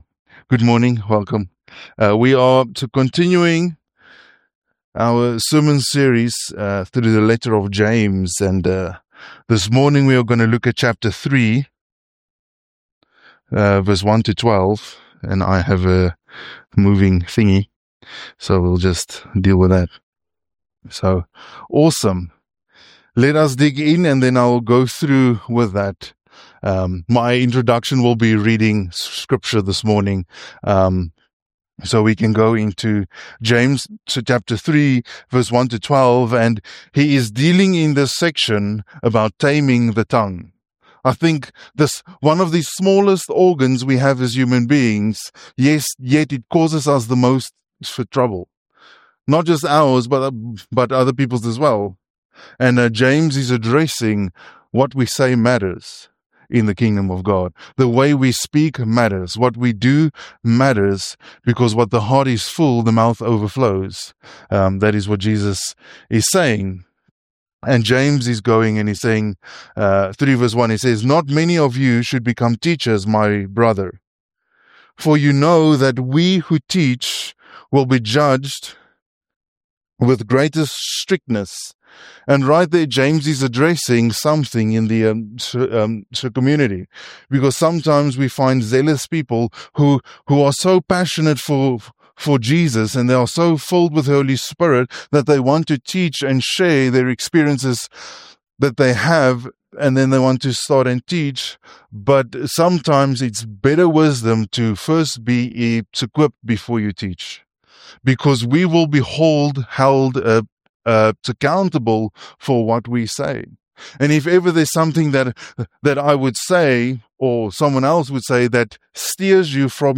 Sermon Series through the letter of James